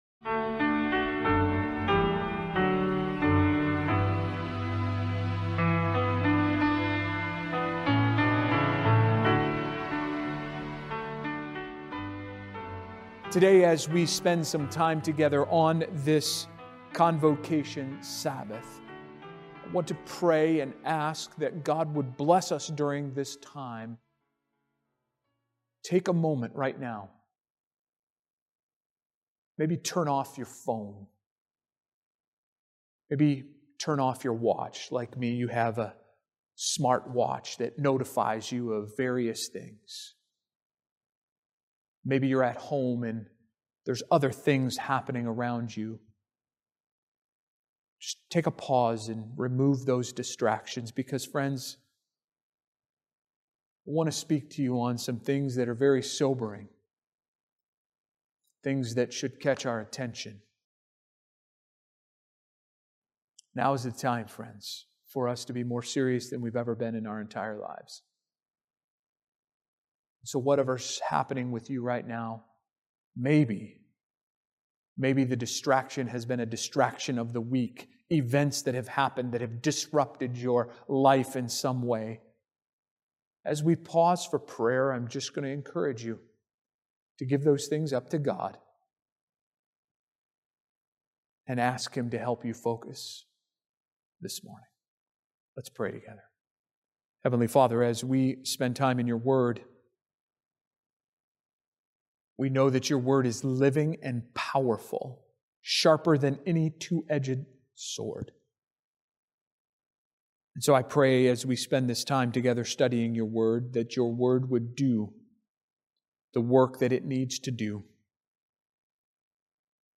This powerful sermon unpacks the parable of the ten virgins, revealing that true spiritual readiness goes beyond head knowledge—it requires a Spirit-filled, active relationship with God. With urgency and scriptural clarity, it calls believers to wake up, seek the Holy Spirit daily, and live with a faith that shines and endures.